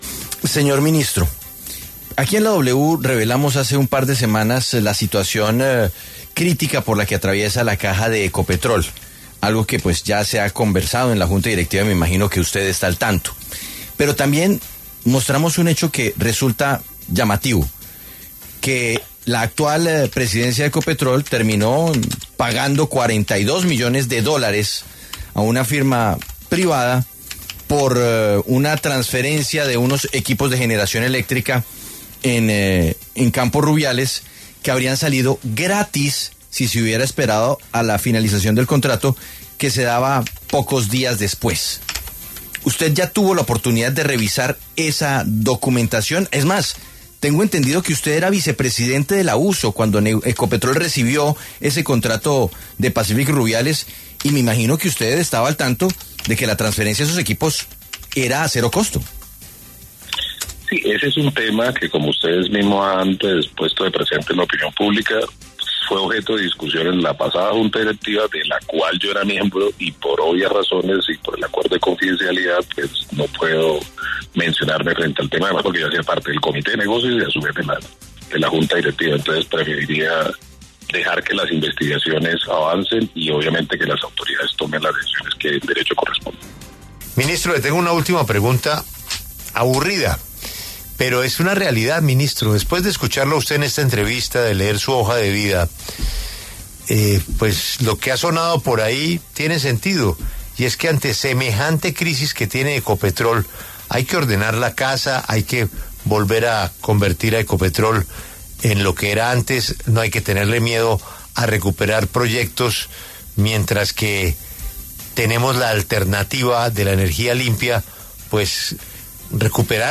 El ministro de Minas y Energía, Edwin Palma, pasó por los micrófonos de La W para hablar sobre varios temas, entre ellos, la crisis de caja a nivel Grupo Empresarial que atraviesa Ecopetrol y la millonaria compra que hicieron por unos equipos de generación eléctrica en Campo Rubiales.